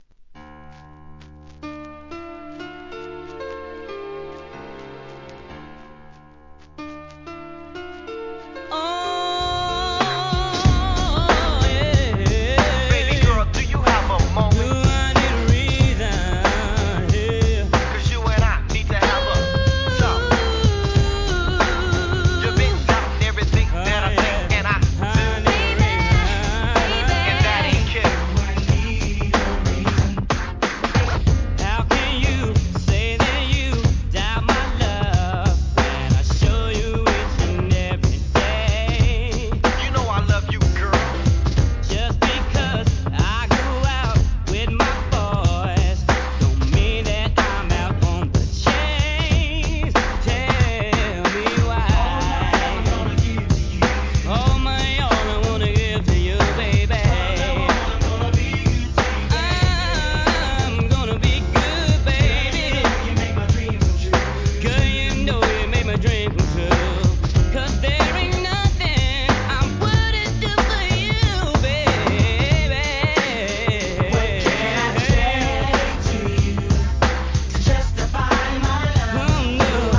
今回はNEW JACK SWING特集!!!